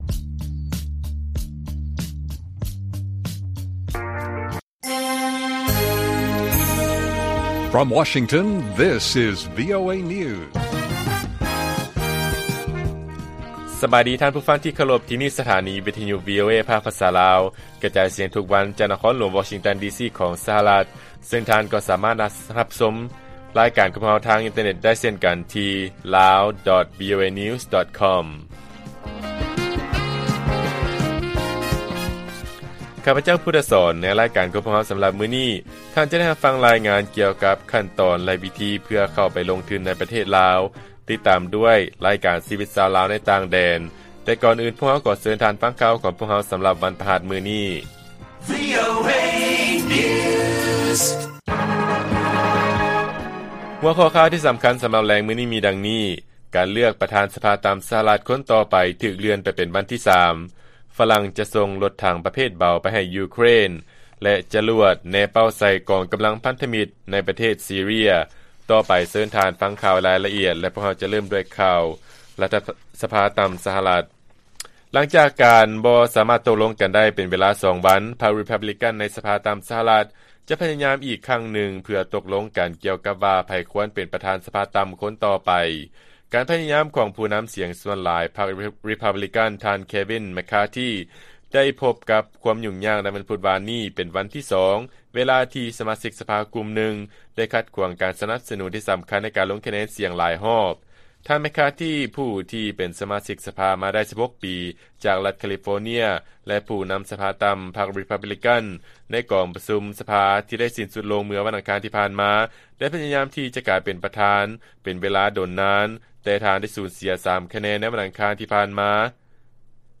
ວີໂອເອພາກພາສາລາວ ກະຈາຍສຽງທຸກໆວັນ, ຫົວຂໍ້ຂ່າວສໍາຄັນໃນມື້ນີ້ມີ: 1. ການເລືອກປະທານສະພາຕ່ຳ ສະຫະລັດ ຄົນຕໍ່ໄປຖືກເລື່ອນໄປເປັນວັນທີ 3, 2. ຝຣັ່ງ ຈະສົ່ງລົດຖັງປະເພດເບົາ ໄປໃຫ້ຢູເຄຣນ, ແລະ 3. ຈະຫຼວດແນເປົ້າໃສ່ກອງກຳລັງພັນທະມິດ ໃນປະເທດ ຊີເຣຍ.